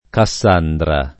kaSS#ndra] pers. f. mit., cogn., top. (Gr.) — dal ricordo dell’infelice figlia di Priamo il nome di c. (perlopiù con c- minusc.) per «inascoltato profeta di sventure»: detto d’uomini non meno che di donne (s. f., anche in tal caso; pl. -dre)